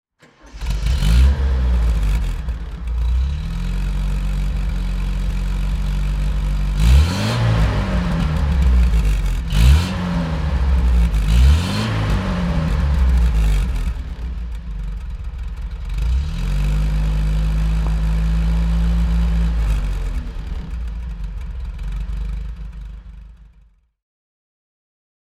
Triumph TR7 Convertible (1982) - Starten und Leerlauf
Triumph_TR7_1981.mp3